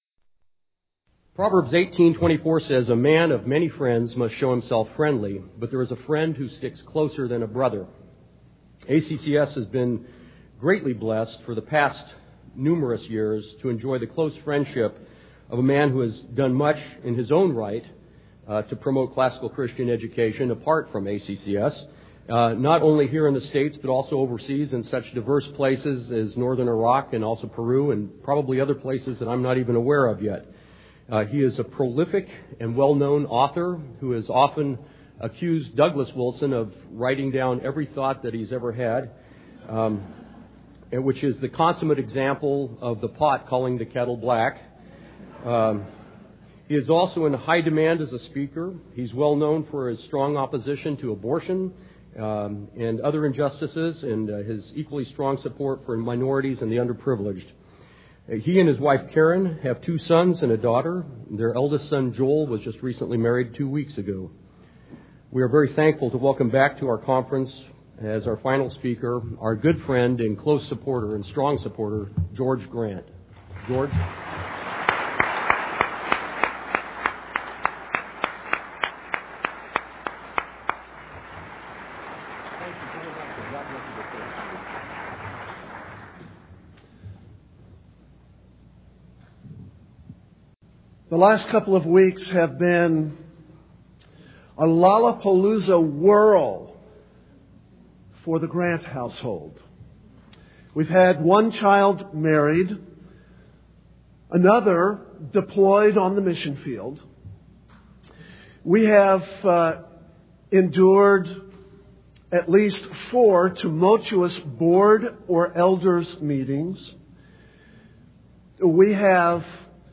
2004 Plenary Talk | 0:48:05 | All Grade Levels